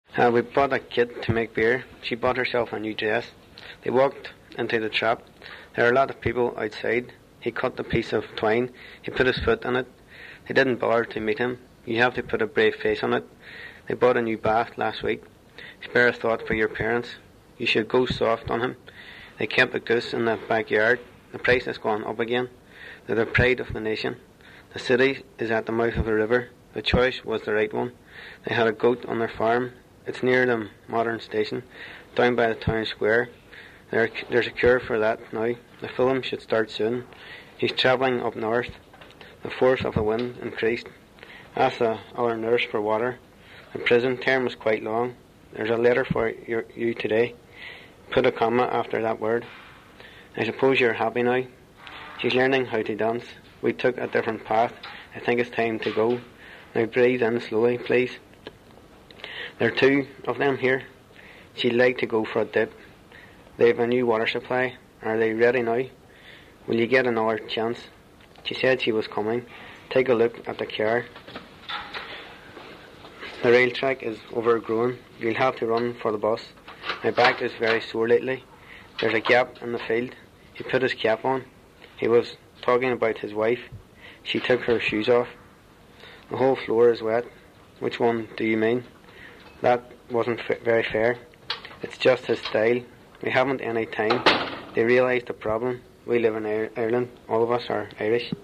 Derry accent, young male
The only leading change among the Catholics in Derry is the shift of intervocalic [ð] to a lateral [l] (a change which is clearly attested in the recordings for A Sound Atlas of Irish English, Hickey 2004).
Derry_Male_c20.mp3